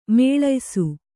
♪ mēḷaysu